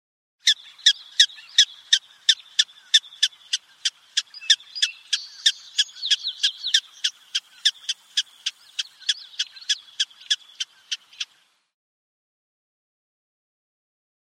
Щебечущая луговая собачка
• Категория: Суслик и луговая собачка
• Качество: Высокое
На этой странице вы можете прослушать звук щебечущая луговая собачка.